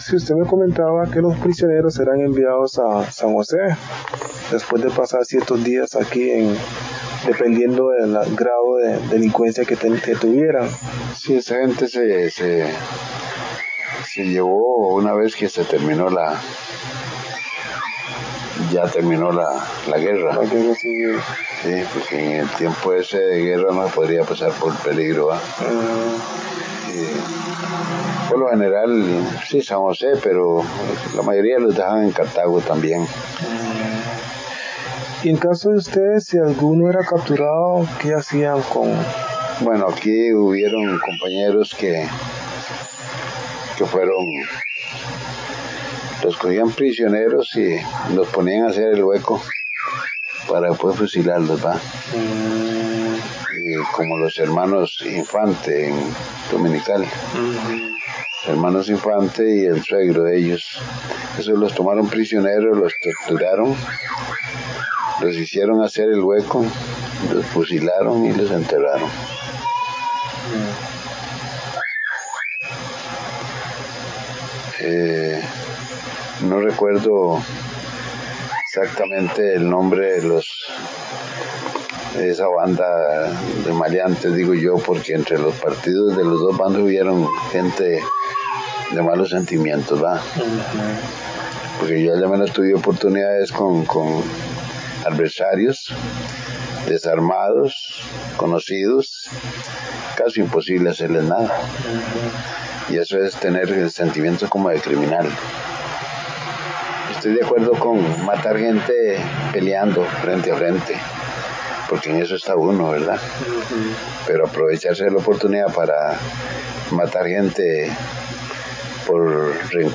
Notas: Casete de audio y digital